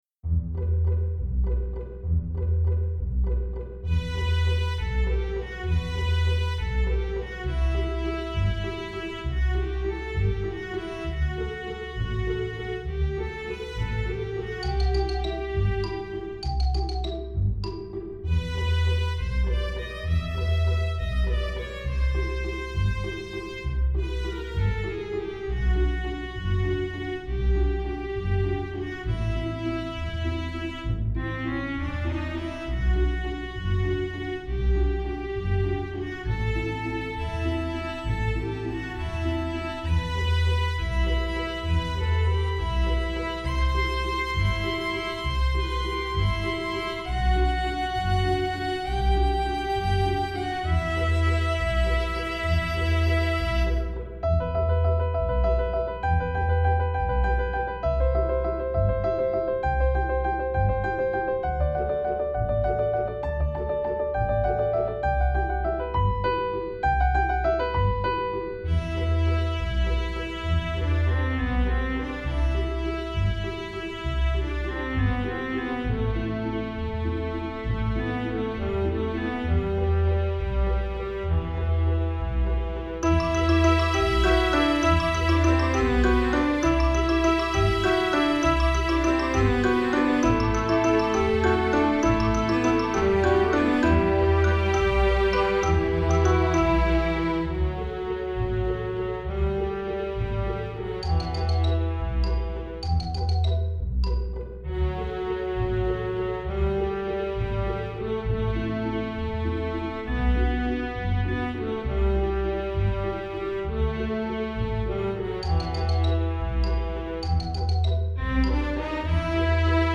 Le but étant de faire une OST... d'un jeu qui n'existe pas !